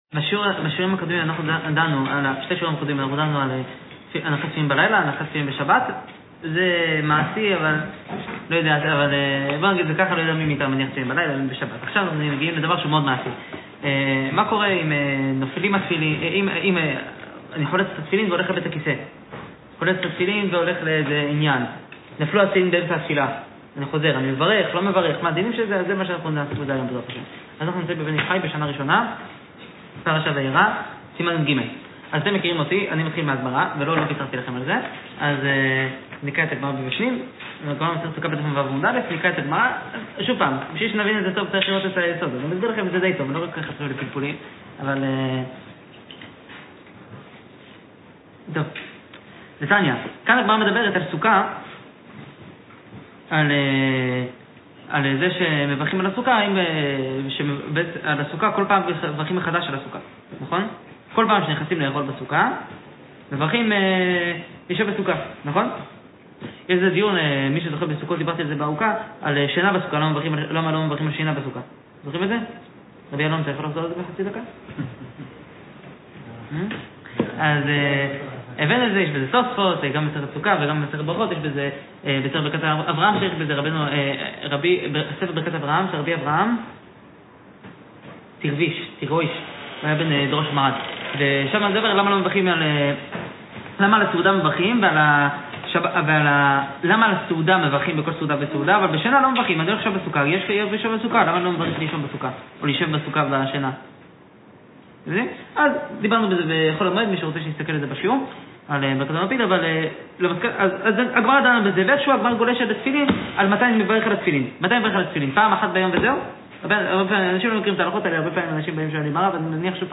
שעורי תורה